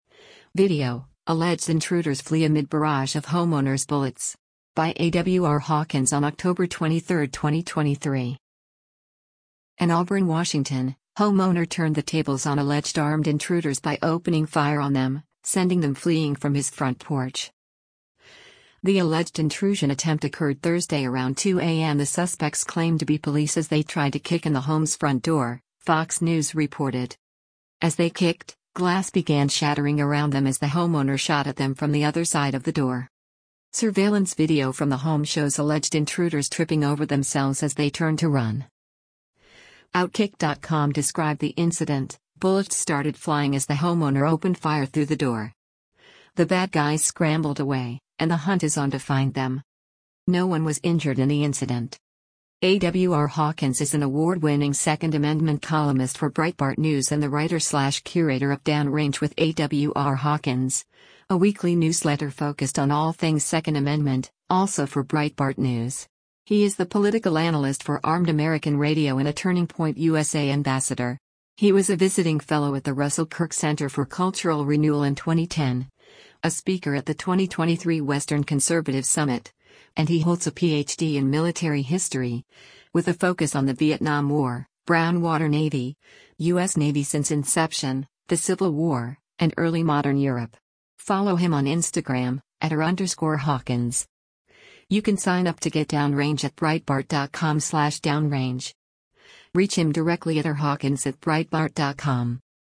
As they kicked, glass began shattering around them as the homeowner shot at them from the other side of the door.
Surveillance video from the home shows alleged intruders tripping over themselves as they turned to run: